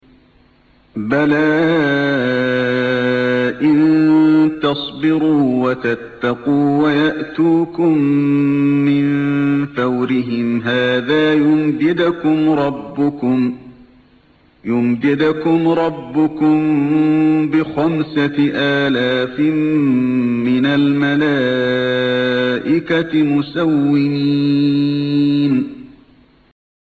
1- رواية حفص عن عاصم
أستمع للشيخ علي الحذيفي يقرأ من هنا